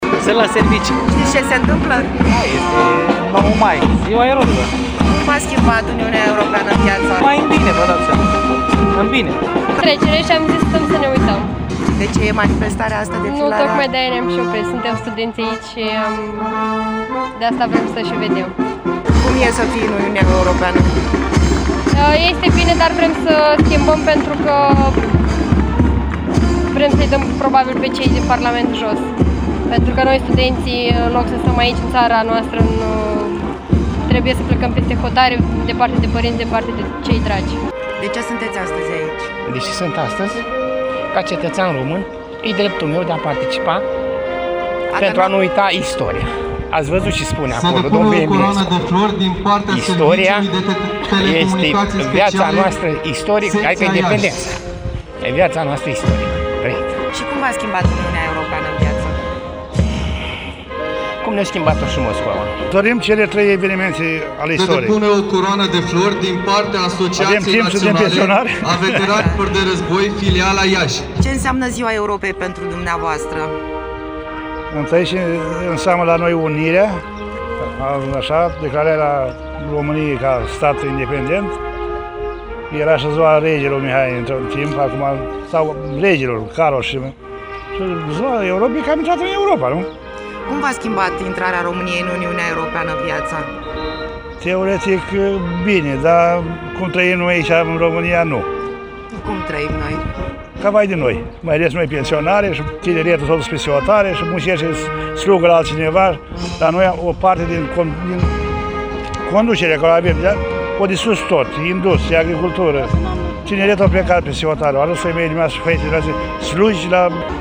Alții, mai virstnici, da. Cum le-a schimbat viața Uniunea Europeană?